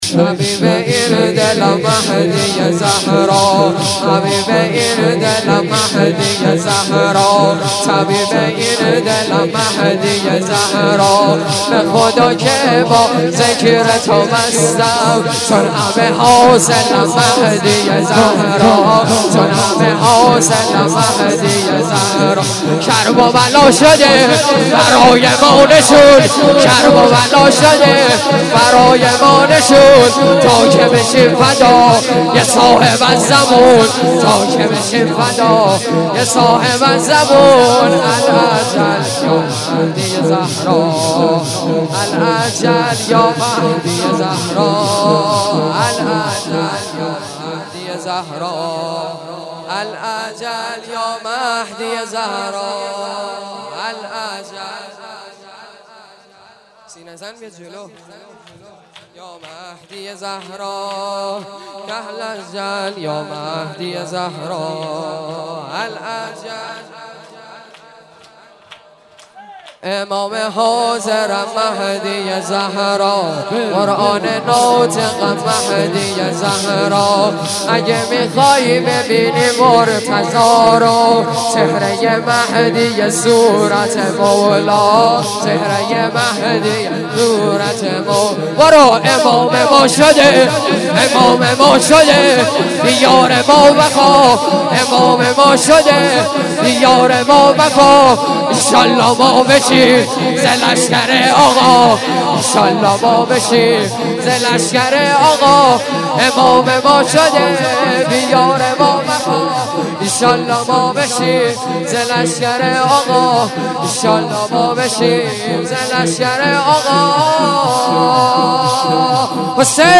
• شور امام زمان ع زیبا